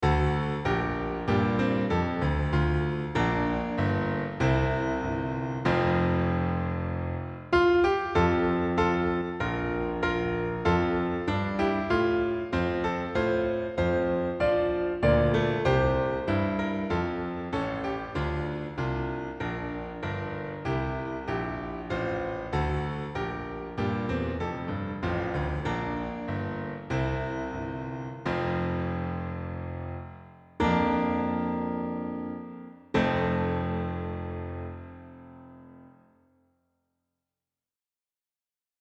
Plus, we're offering accompaniments (most with introductions) that you can play on a cell phone or other device to enjoy by yourself or at your family table.